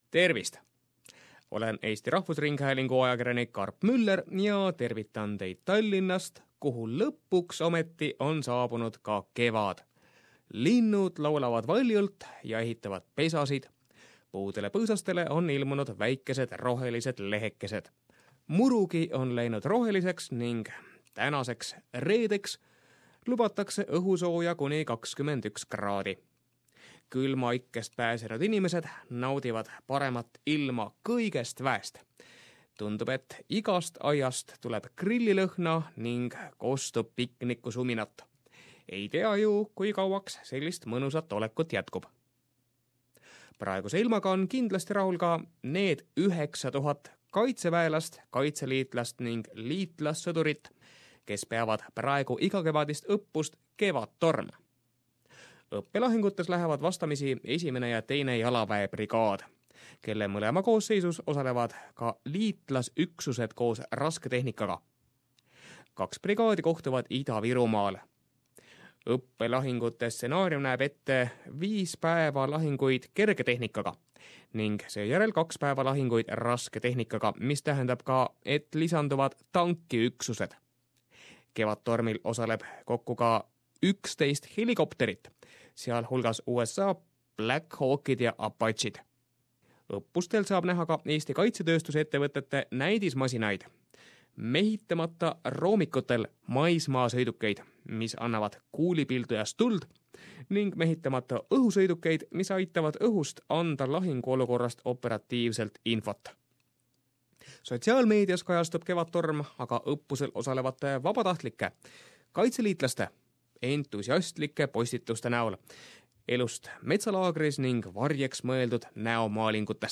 Estonian News Report